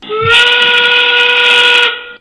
Play, download and share FTC endgame start original sound button!!!!